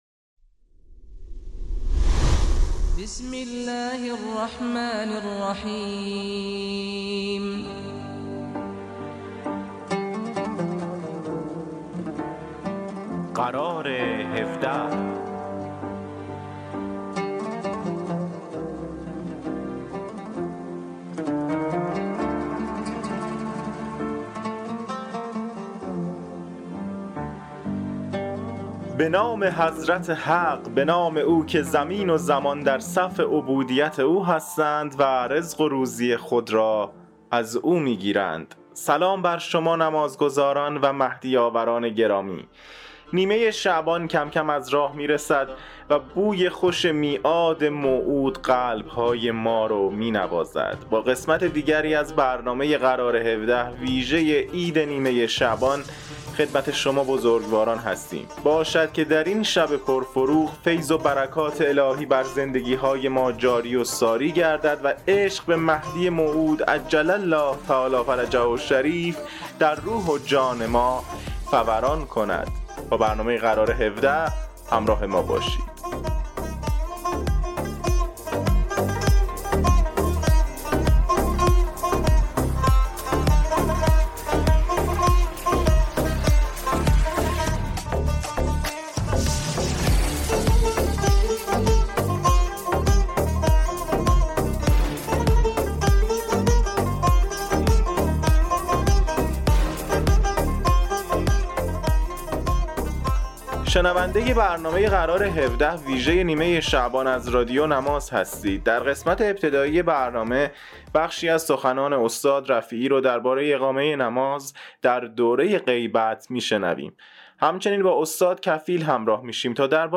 برنامه اینترنتی قرار هفده مجموعه ای از آیتم های نمازی و در مورد بخش های مختلف از نماز، دارای تواشیح، سخنرانی های نمازی، سرود و ترانه، دلنوشته، خاطرات و معرفی کتاب و … است.